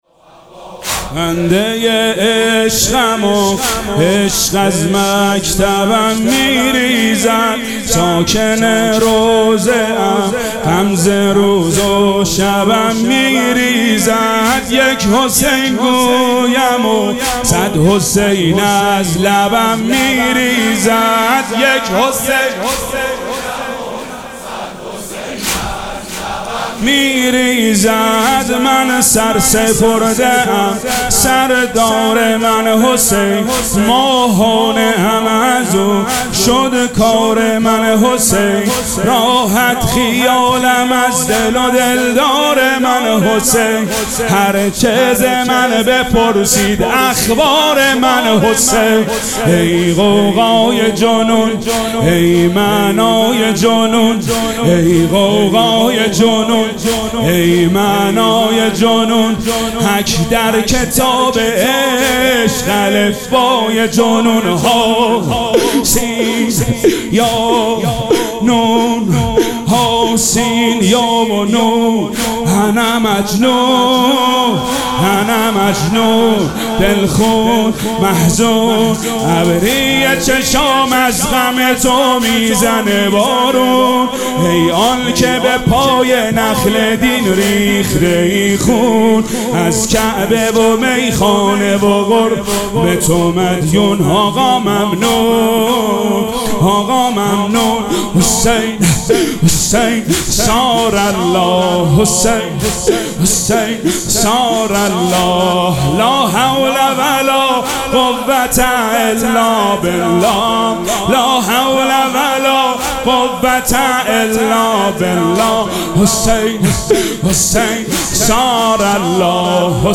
مراسم عزاداری شب سوم محرم الحرام ۱۴۴۷
مداح